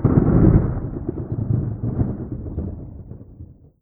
tenkoku_thunder_close05.wav